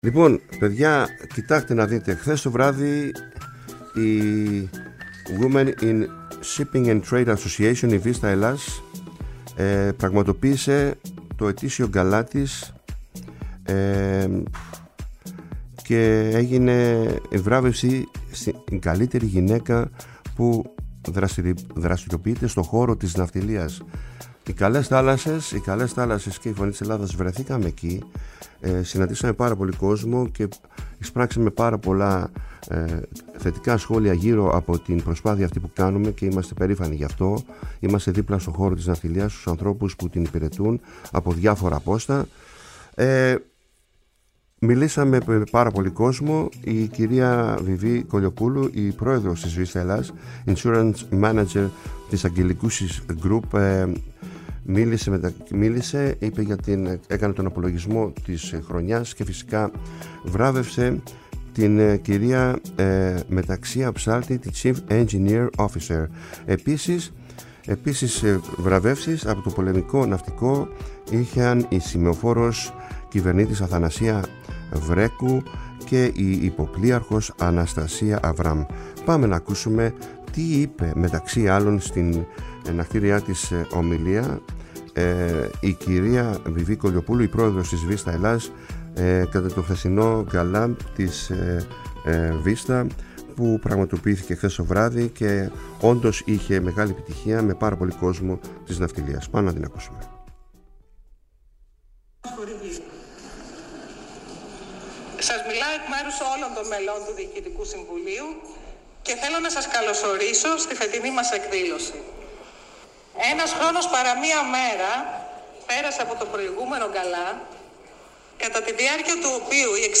Απόσπασμα από την ομιλία
Οι «ΚΑΛΕΣ ΘΑΛΑΣΣΕΣ» και η ΦΩΝΗ ΤΗΣ ΕΛΛΑΔΑΣ ήταν εκεί και κατέγραψαν το γεγονός.